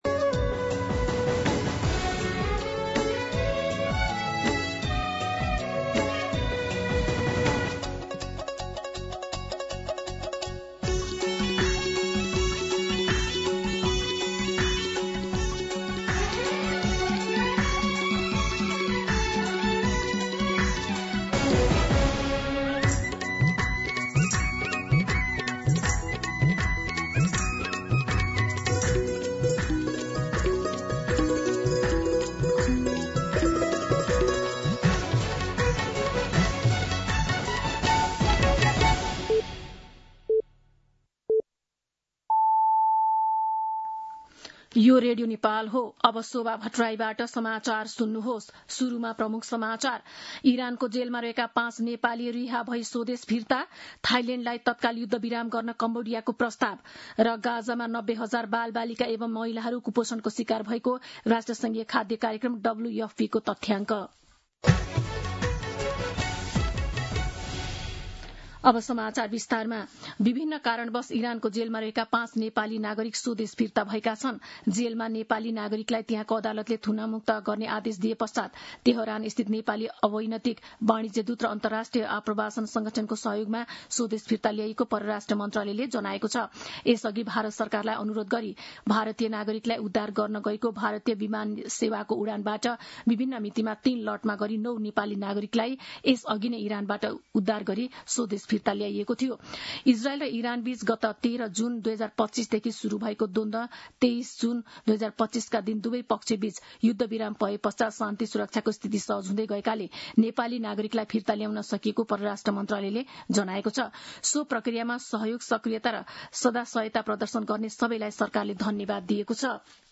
दिउँसो ३ बजेको नेपाली समाचार : १० साउन , २०८२
3-pm-Nepali-News-3.mp3